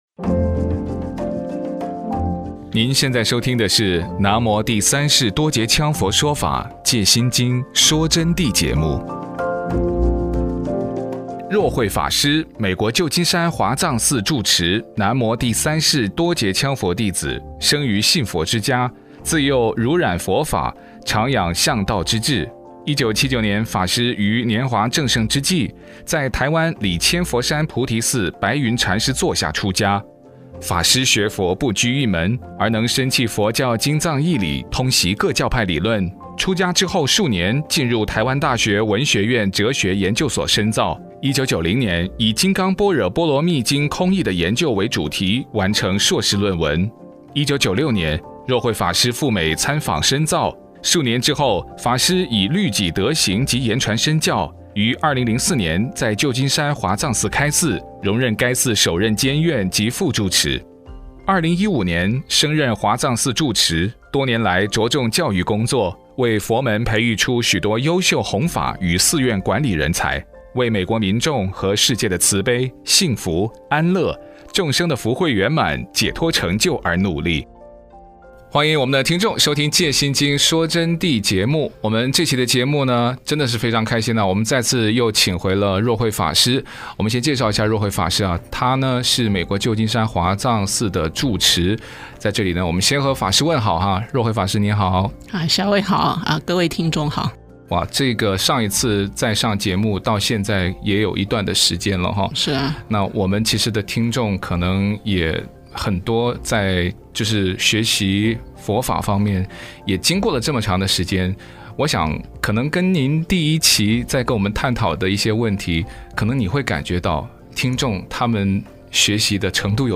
佛弟子访谈（七十四）